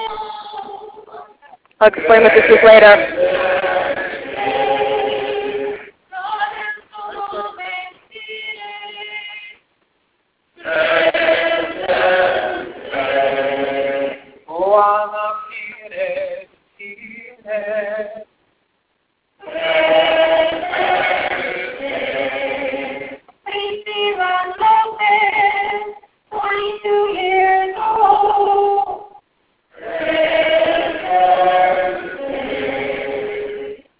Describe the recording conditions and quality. This is a recording from the vigil/march at the gates of Fort Benning, GA where 16,000 protesters solemnly invoke the names and ages of over 1,000 victims of graduates of the US Army School of the Americas. In Latin American tradition, the victims spirits are symbolically declared "presente!